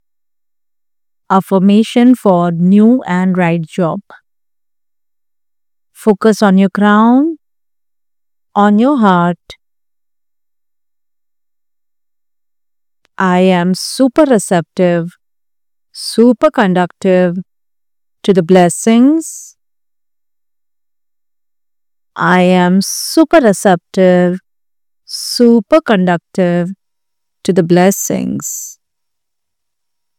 • Energize Your Career Path – Activate focused intention and confidence through a guided affirmation session to accelerate your job search journey.